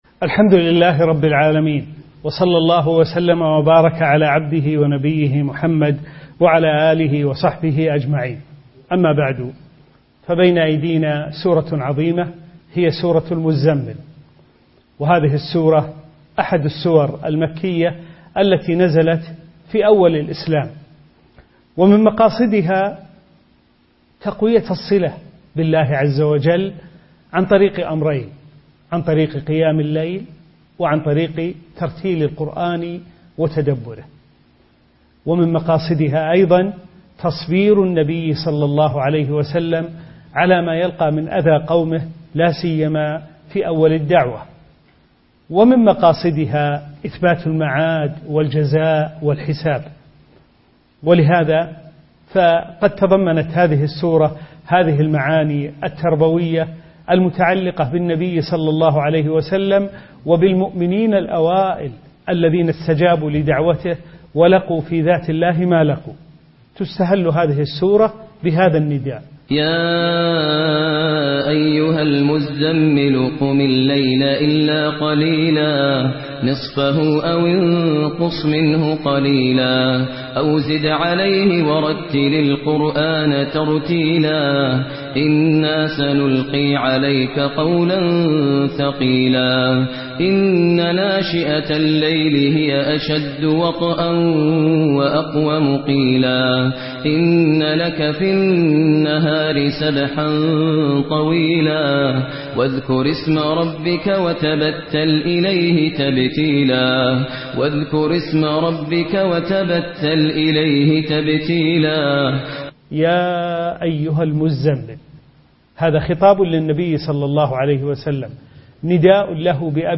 الدرس السادس والعشرون : سورة المزمل: من أول السورة، إلى قوله تعالى: (وَتَبَتَّلْ إِلَيْهِ تَبْتِيلًا).